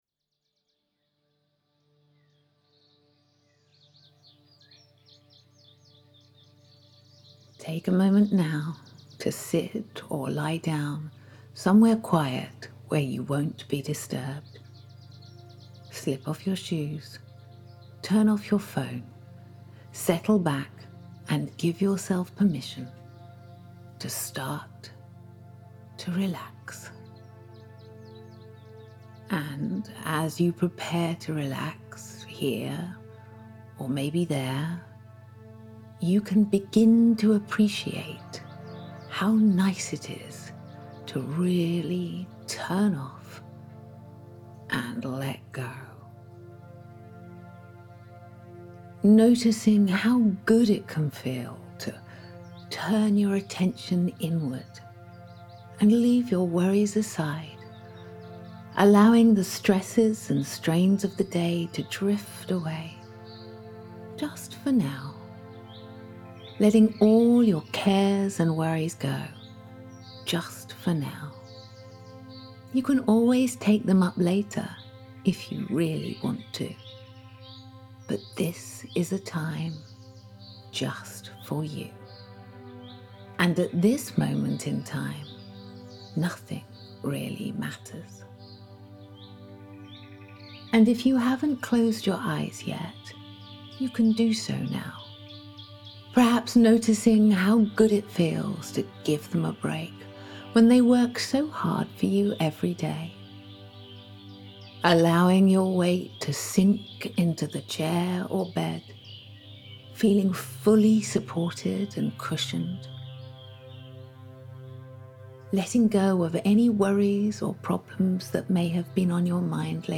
A deep relaxation to discover your own personal power place